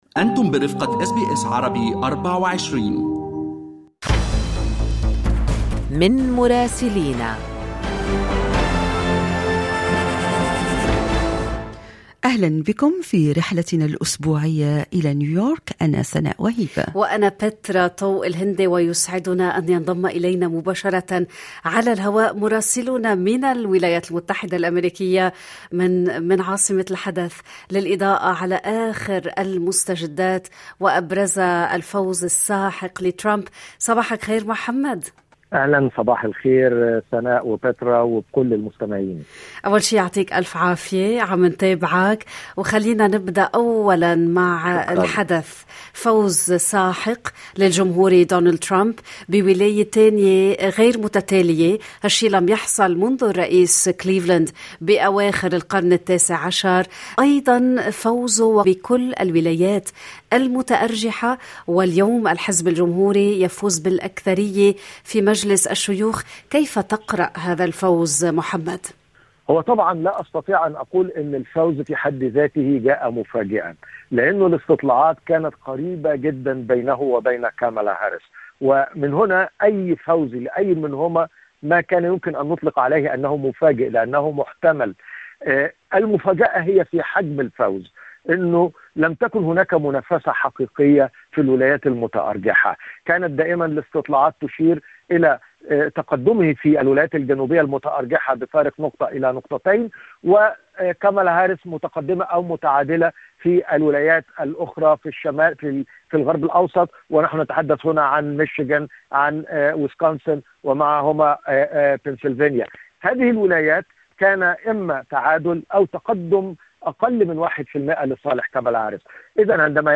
من مراسلينا: أخبار الولايات المتحدة الأمريكية في أسبوع 7/11/2024